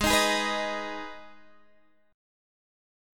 G#m chord